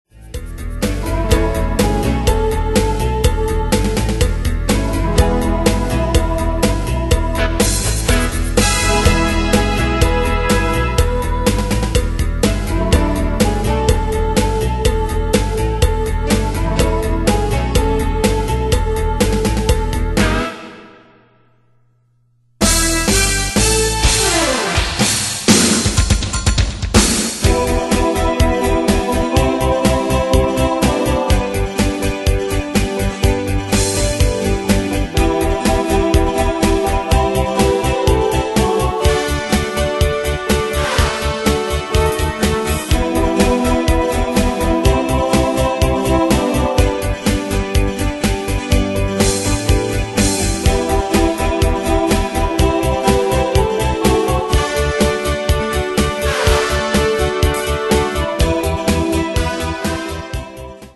Style: PopAnglo Année/Year: 1988 Tempo: 124 Durée/Time: 4.14
Danse/Dance: Rock Cat Id.
Pro Backing Tracks